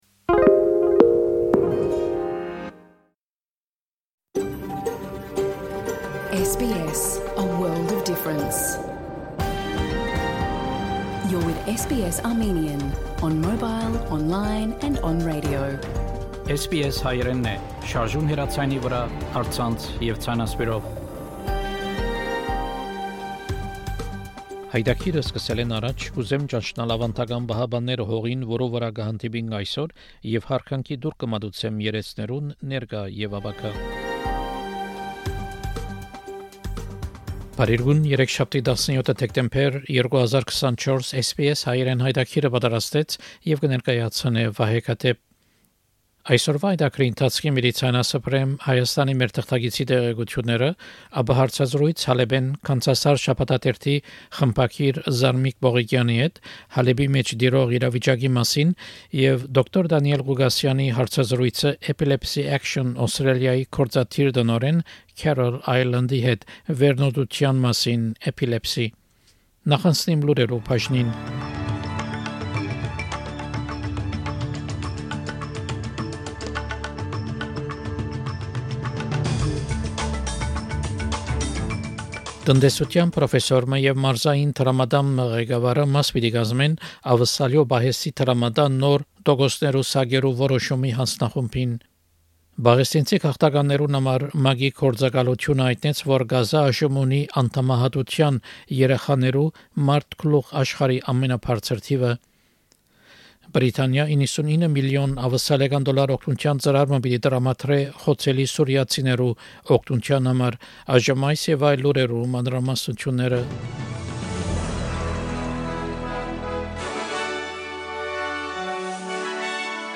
SBS Հայերէնի աւստրալիական և միջազգային լուրերը քաղուած 17 Դեկտեմբեր 2024 յայտագրէն: SBS Armenian news bulletin from 17 December program.